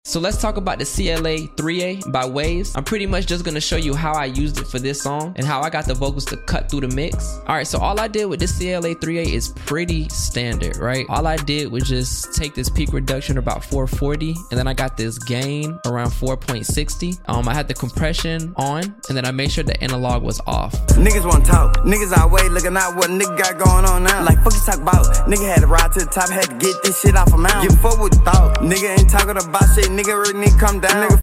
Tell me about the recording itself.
Are you using the CLA-3A plugin in your vocal chain?